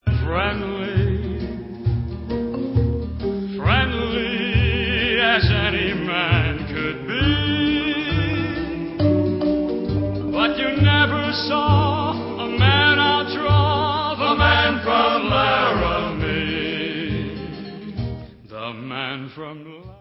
Western soundtracks